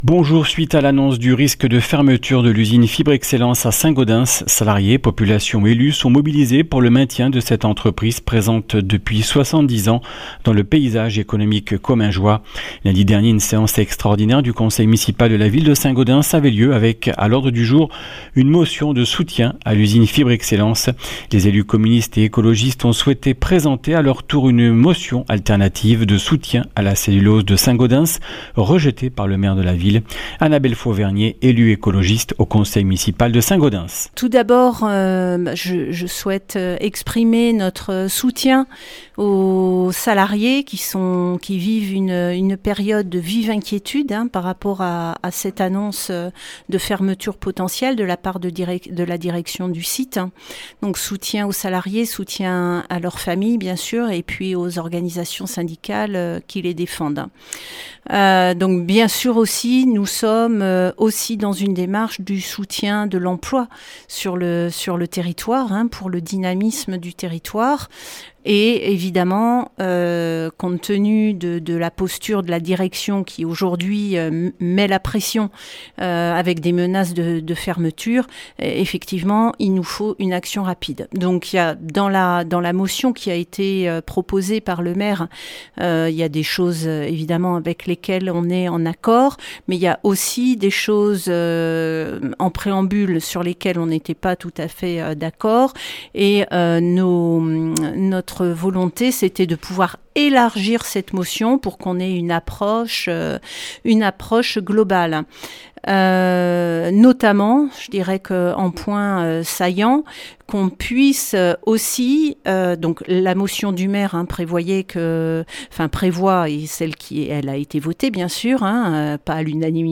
Journaliste